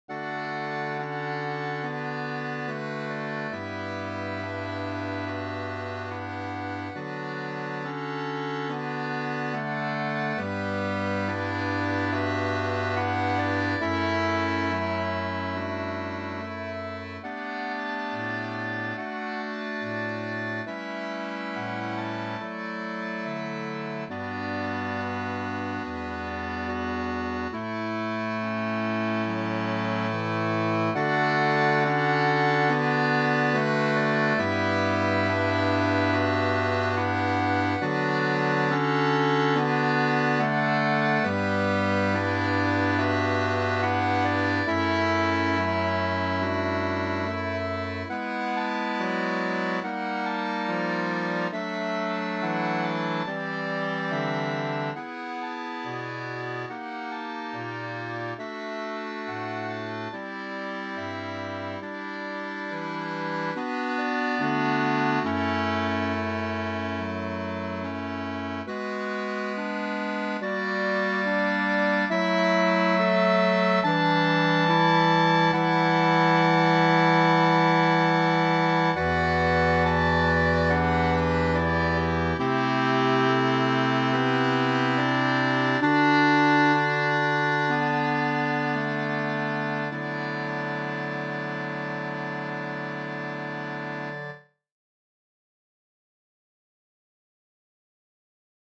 Ensemble de Clarinettes
Musiques chinoises